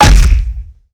BaseballBatHit.wav